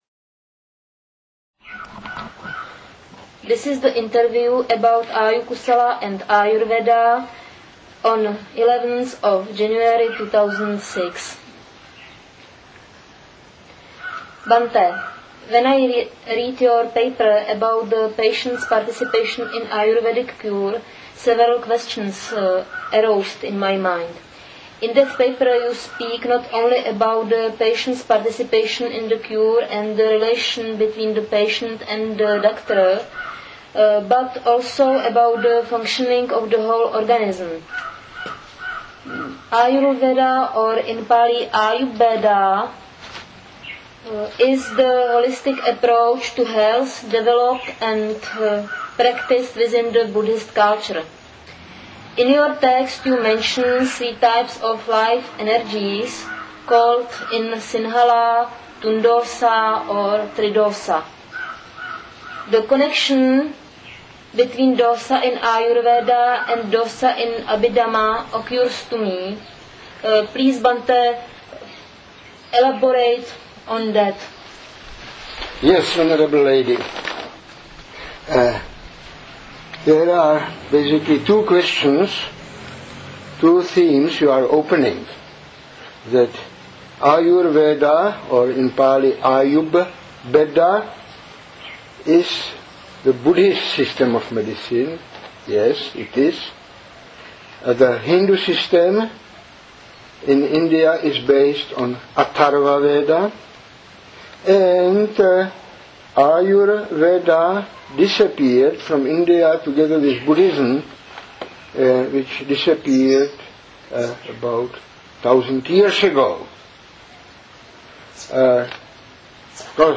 Ayurveda and Ayukusala - Interview (English)